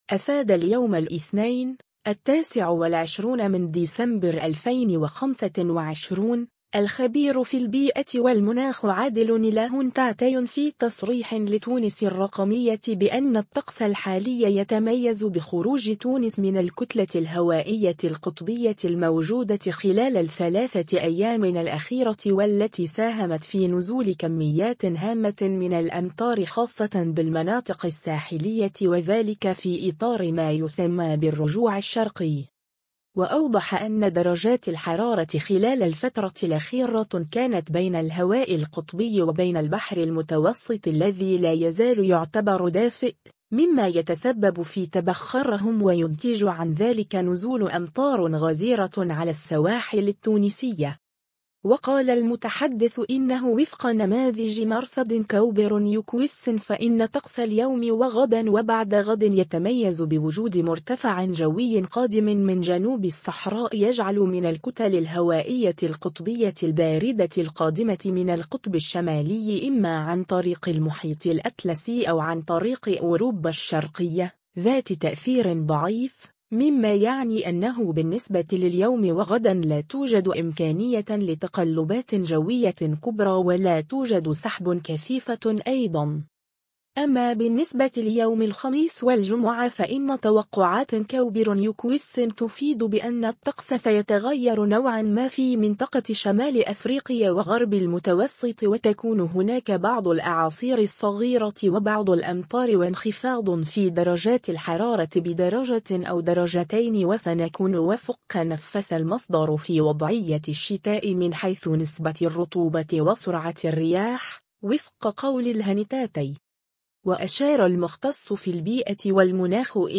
مختصّ في المناخ يكشف تفاصيل حالة الطّقس خلال هذا الأسبوع [فيديو]